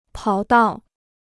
跑道 (pǎo dào) Free Chinese Dictionary